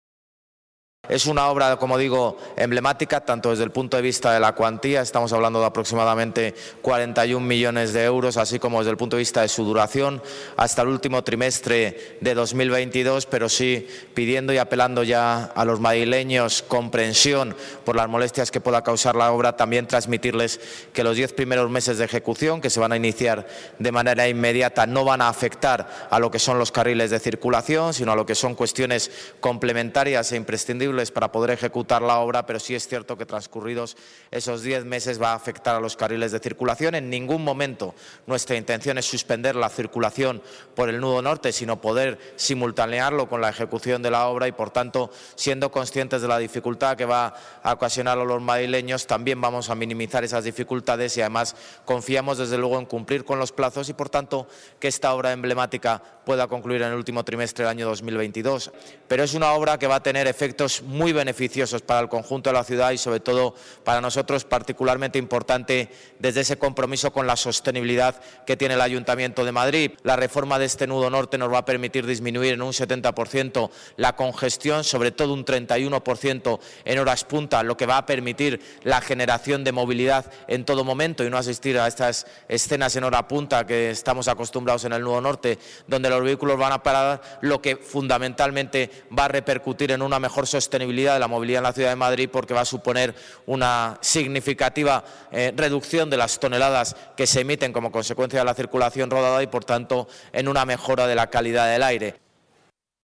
El alcalde ha explicado los detalles de la obra a 132 metros de altura
Nueva ventana:José Luis Martínez-Almeida, alcalde de Madrid